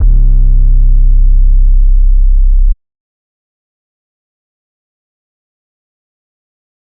[ACD] - Lex 808 (1).wav